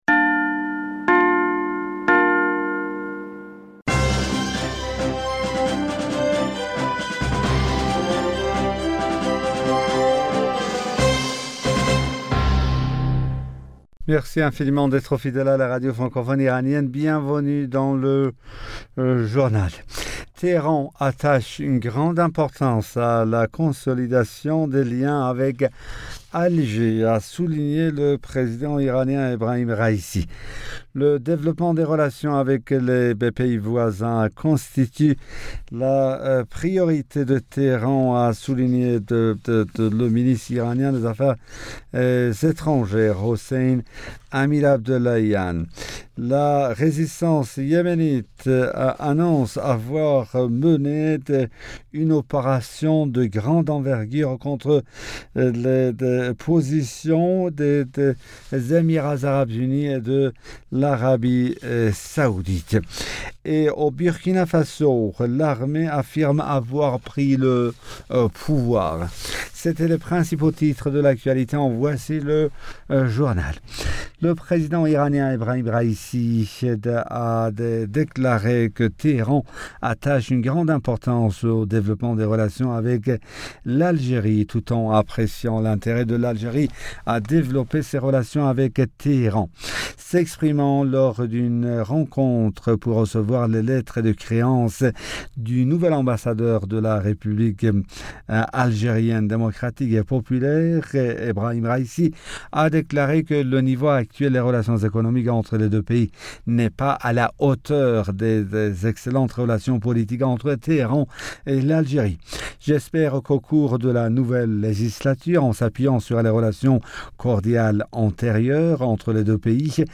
Bulletin d'information Du 25 Janvier 2022